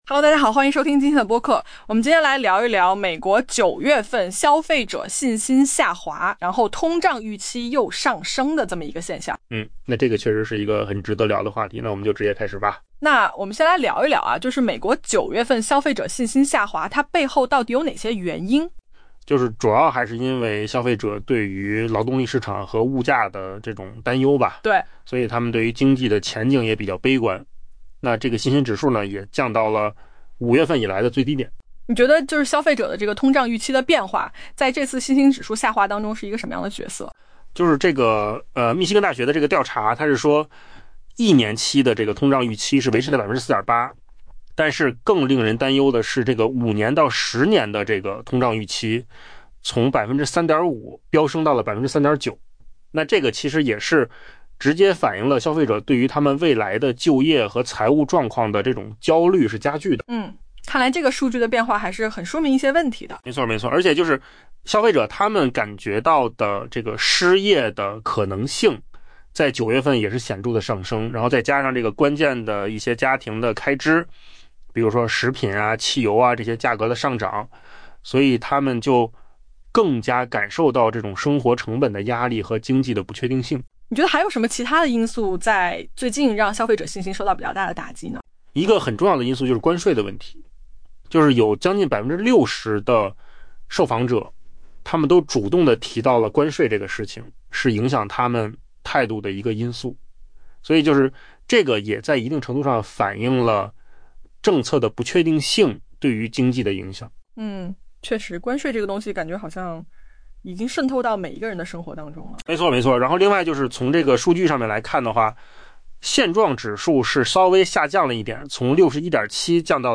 AI 播客：换个方式听新闻 下载 mp3 音频由扣子空间生成 美国 9 月份消费者信心降至 5 月以来的最低水平，且长期通胀预期连续第二个月上升 ，原因是对劳动力市场和物价的担忧拖累了经济前景。